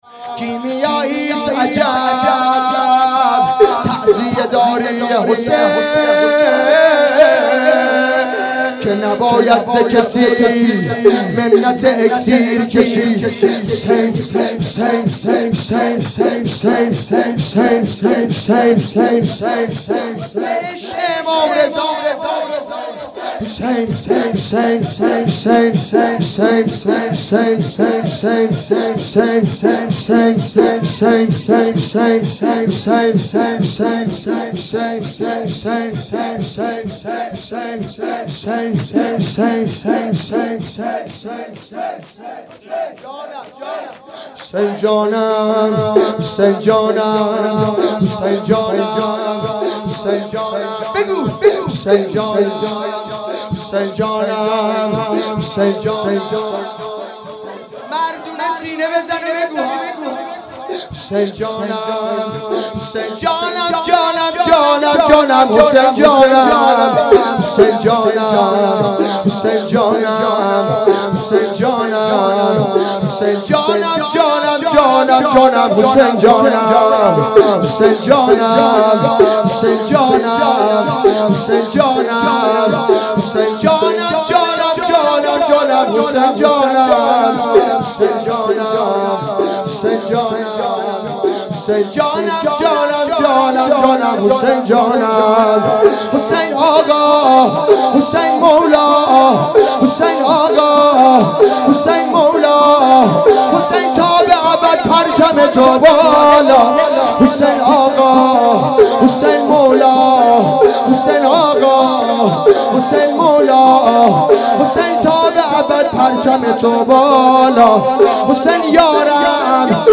اقامه عزای شهادت امام حسن مجتبی علیه السلام